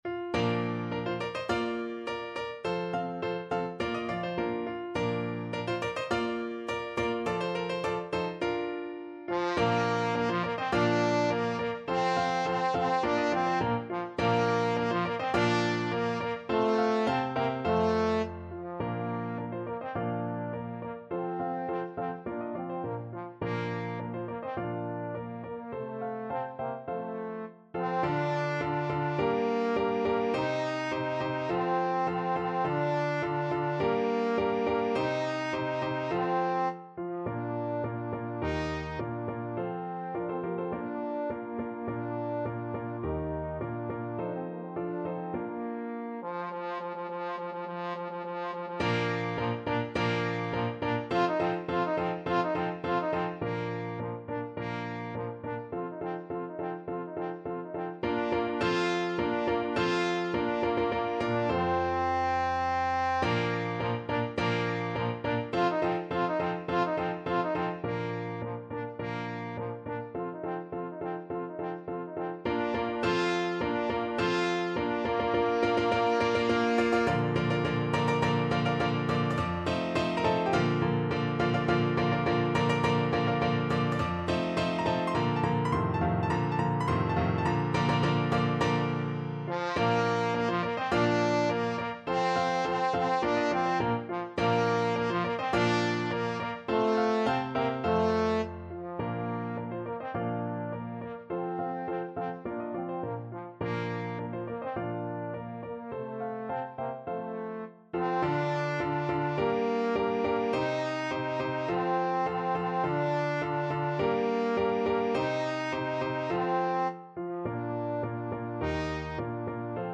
~ = 100 Molto vivace =104
F4-F5
2/4 (View more 2/4 Music)
Classical (View more Classical Trombone Music)